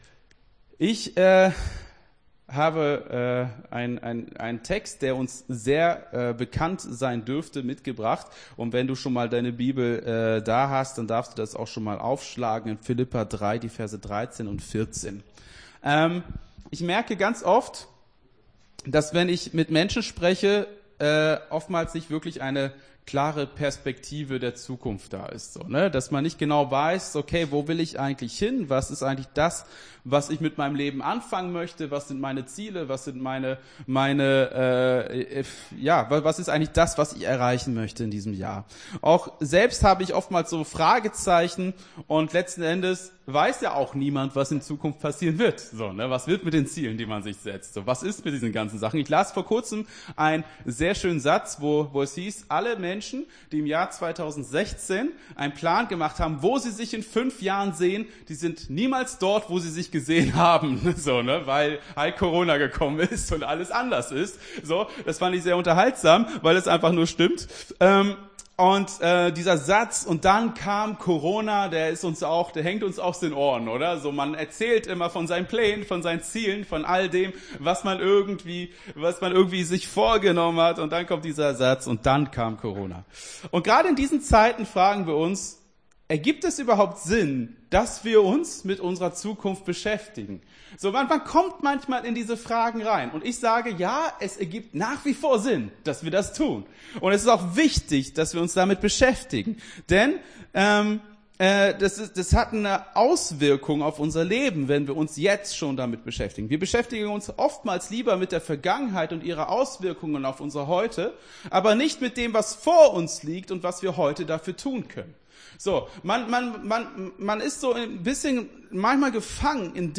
Gottesdienst 16.01.22 - FCG Hagen